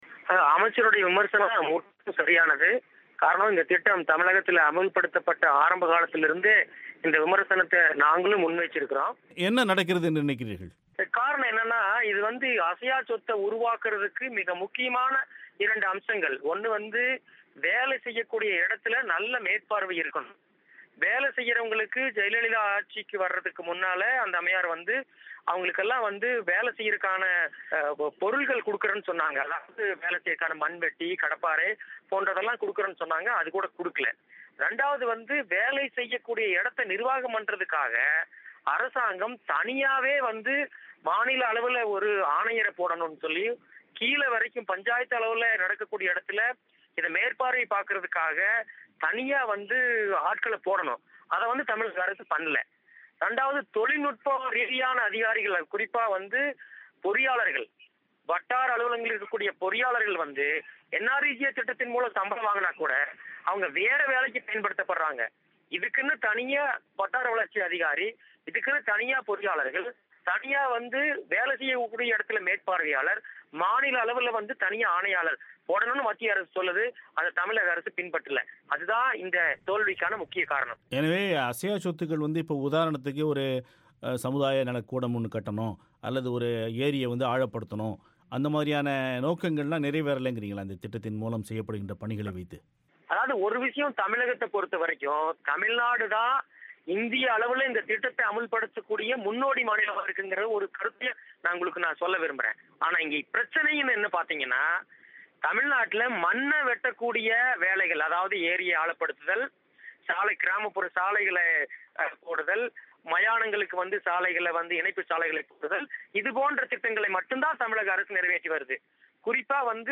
மகாத்மா காந்தி ஊரக வேலை வாய்ப்புத் திட்டம் தமிழகத்தில் அமல்படுத்தப்படும் முறையில் குறைகள் இருப்பதாக இந்திய கிராமப்புற மேம்பாட்டு அமைச்சர் ஜெய்ராம் ரமேஷ்,முதல்வர் ஜெயல்லிதாவுக்கு எழுதியுள்ள கடிதத்தில் கூறியிருக்கிறார் .இந்த விமர்சனம் சரியானதா? ஒரு பேட்டி